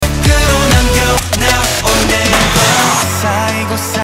DIRTY_drum_fill_tom_snare_130